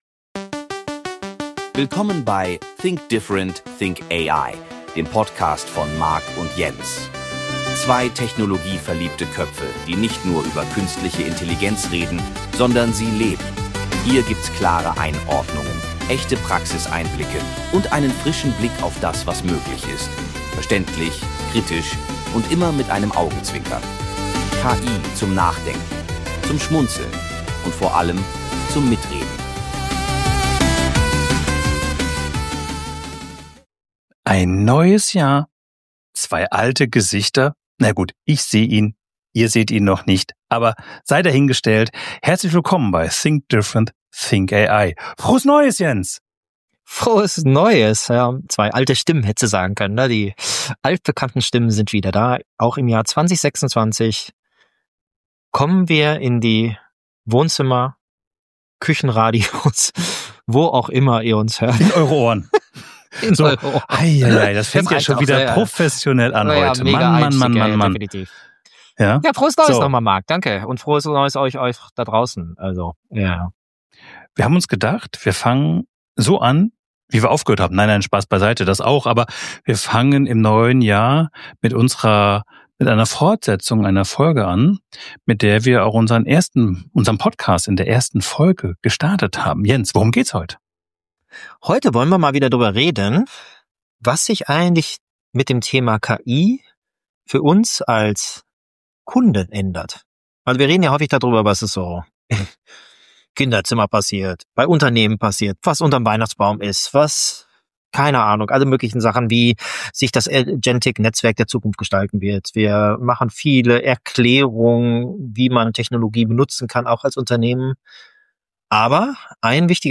Ein neues Jahr, ein vertrautes Duo – und ein Thema, das uns alle betrifft: Was passiert, wenn künstliche Intelligenz nicht nur für Unternehmen, sondern auch für Kunden zum Spielveränderer wird?